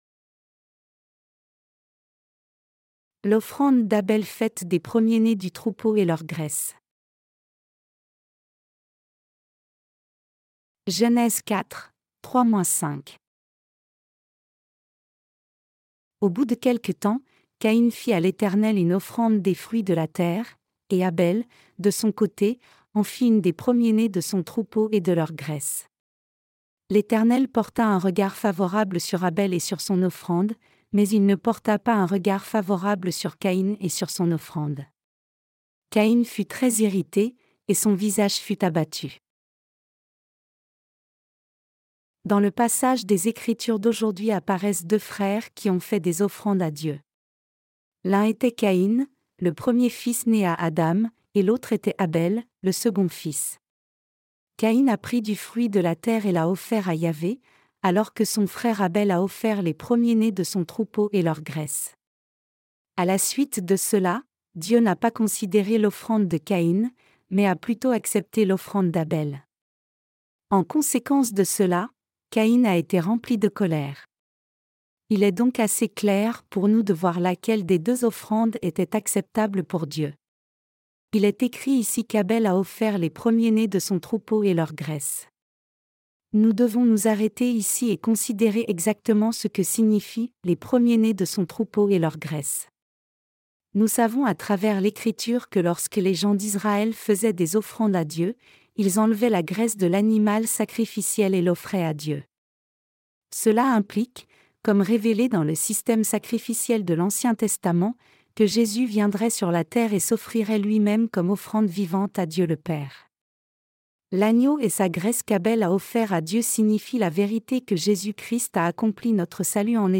Sermons sur la Genèse (V) - LA DIFFERENCE ENTRE LA FOI D’ABEL ET LA FOI DE CAÏN 2.